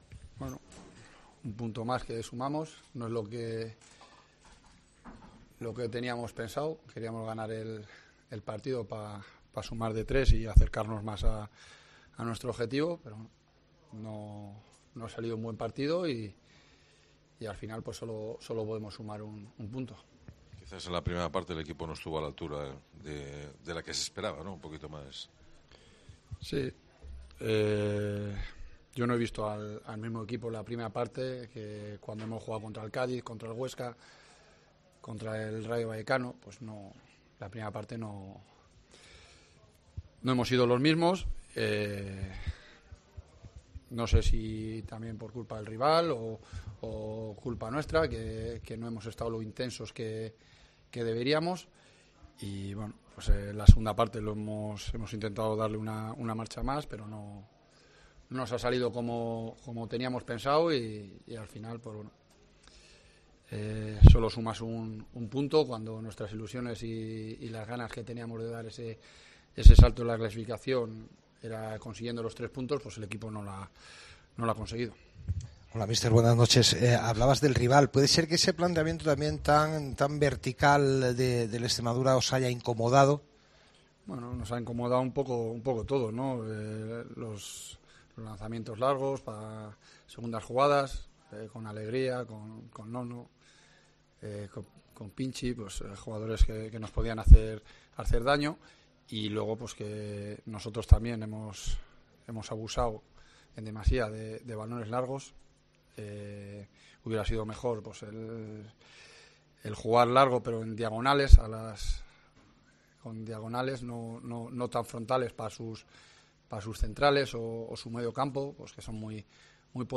POSTPARTIDO
Escucha aquí las palabras del míster de la Deportiva Ponferradina, Jon Pérez Bolo, tras el empate 0-0 ante el Extremadura